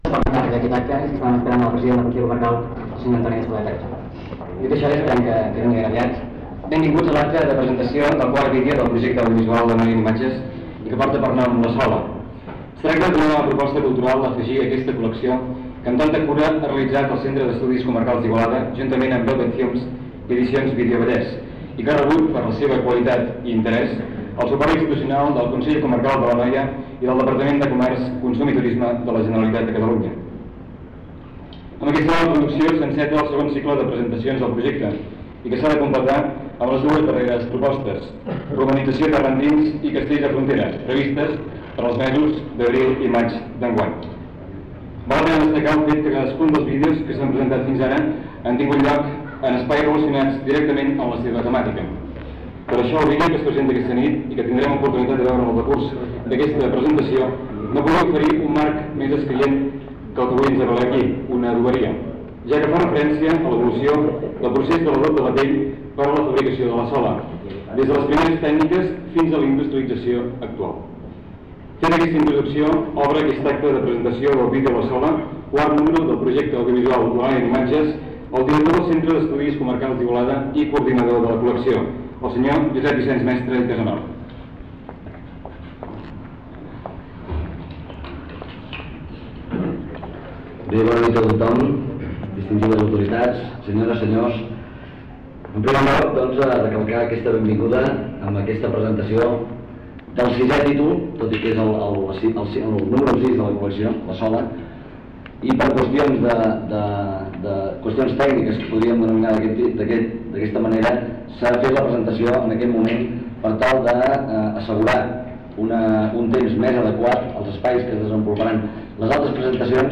Corpus Oral de Registres (COR). CULT1. Presentació d'un vídeo